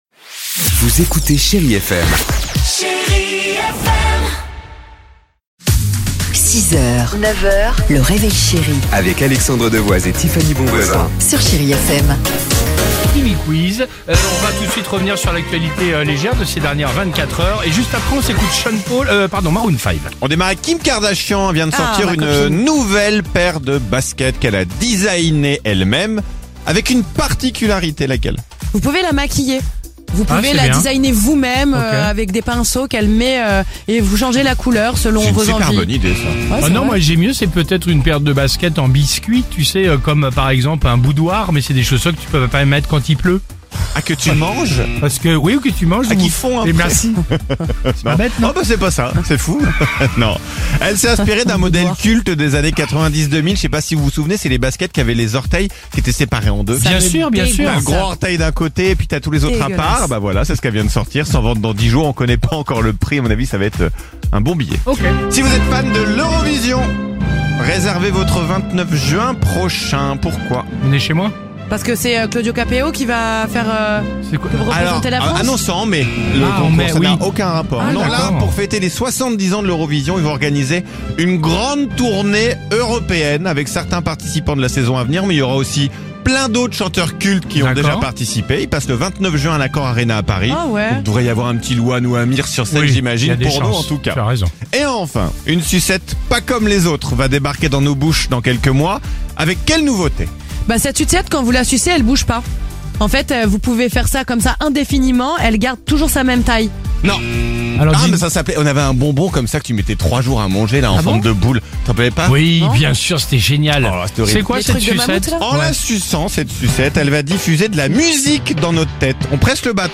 Genres: Comedy